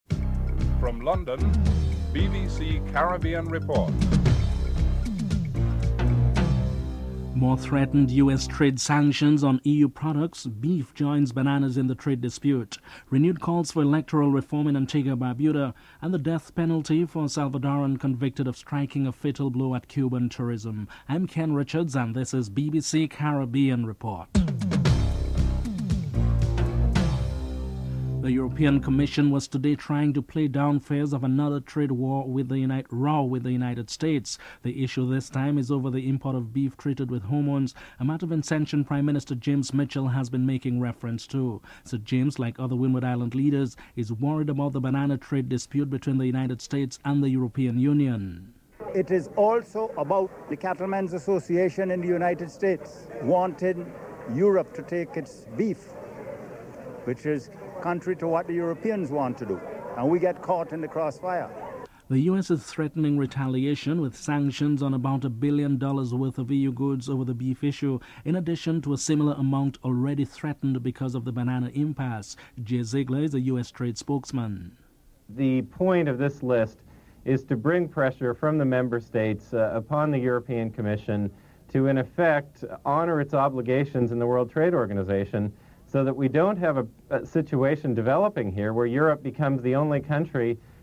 Home Secretary Jack Straw comments on one controversial proposal to prosecute persons for racial offences in private places as recommended by the report (12: 26 – 13:35)